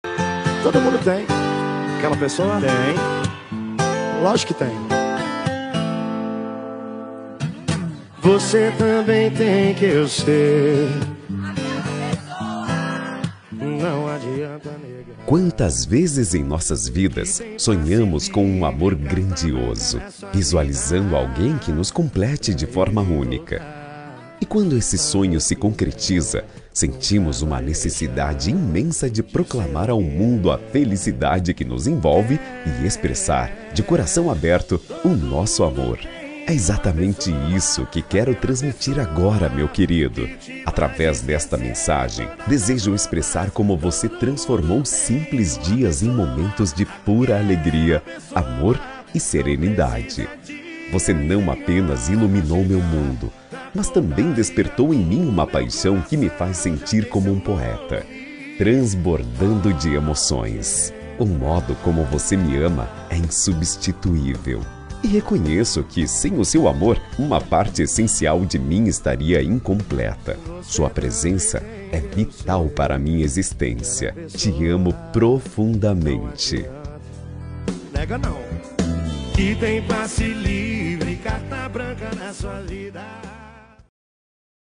Telemensagem Romântica – Voz Masculina – Cód: 911301 – Linda
9113-rom-masc.m4a